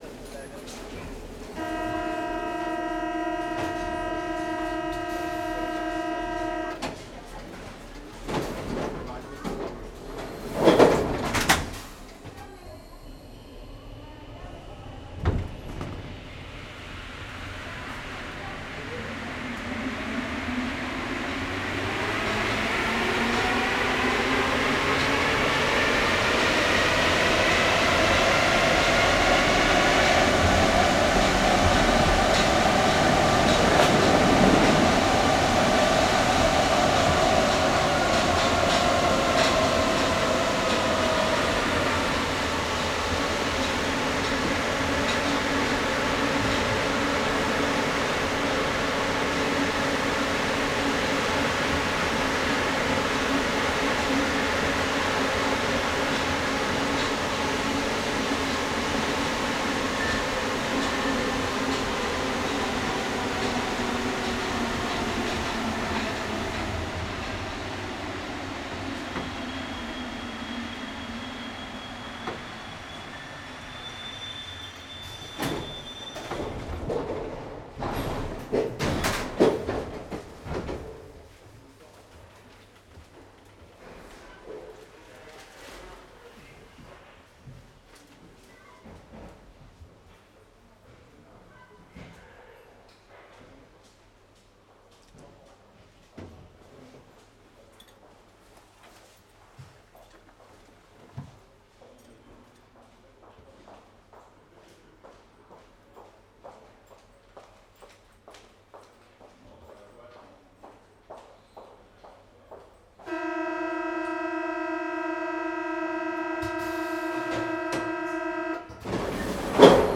subway.L.wav